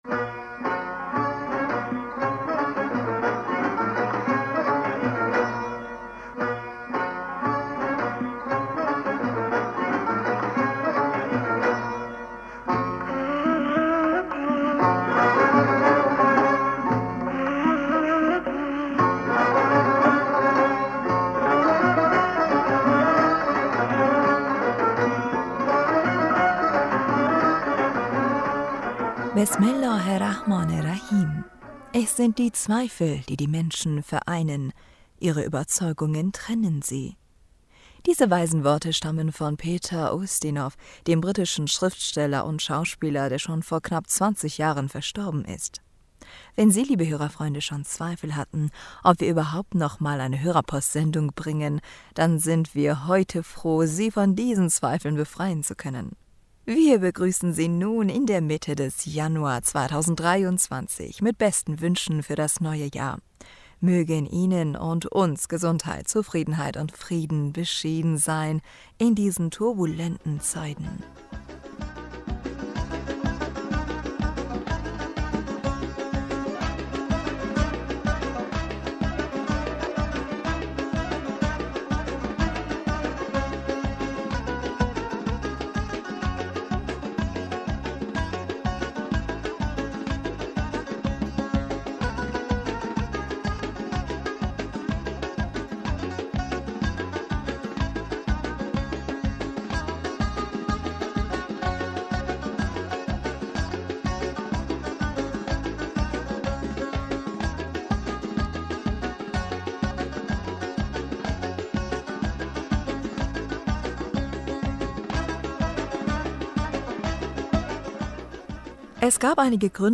Hörerpostsendung am 15. Januar 2023